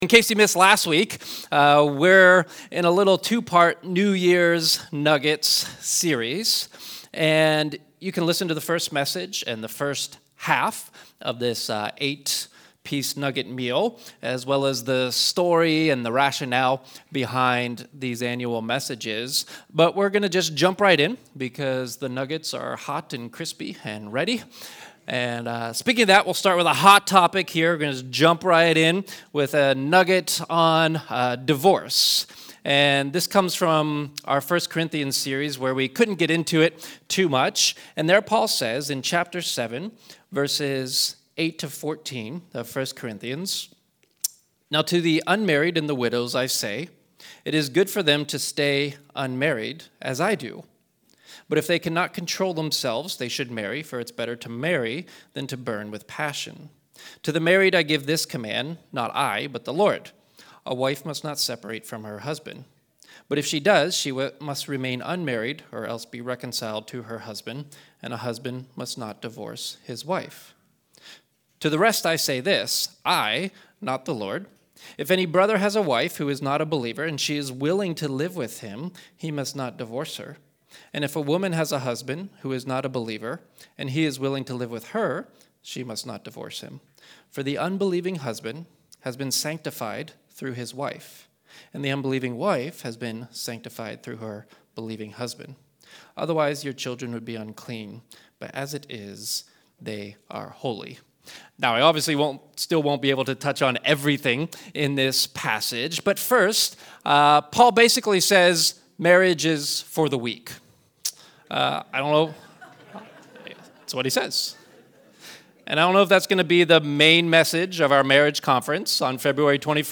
It’s our annual New Year’s nuggets messages!